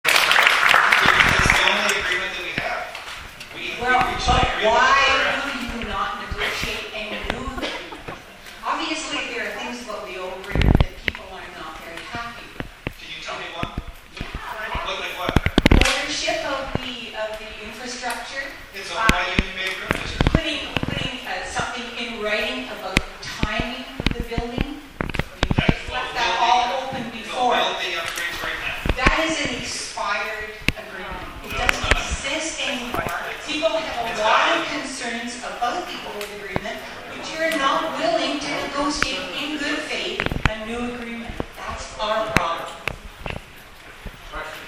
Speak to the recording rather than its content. This clip is also from the March 2016 meeting hosted by Kensington Island Properties.